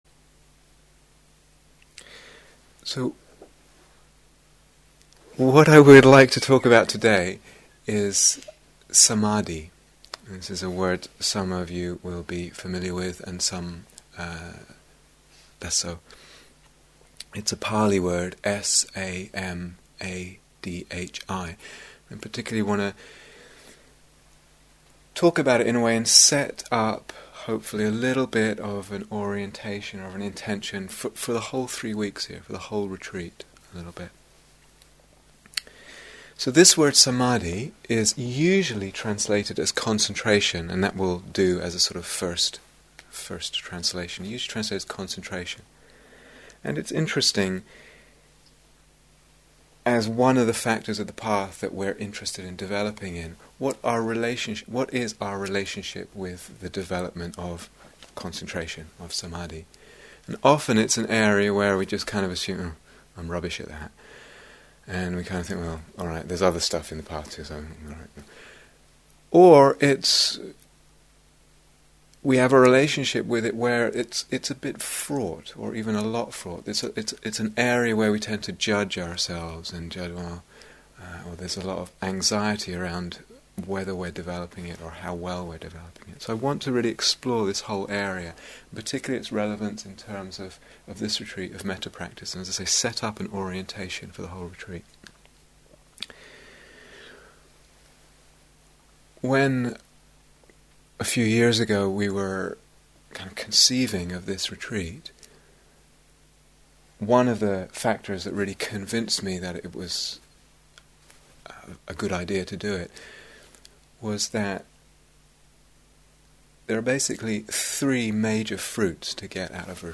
Here is the full retreat on Dharma Seed This series of talks and guided meditations explores the development of the practices of both Lovingkindness and Compassion, with particular emphasis on the radical possibilities of Awakening that they bring.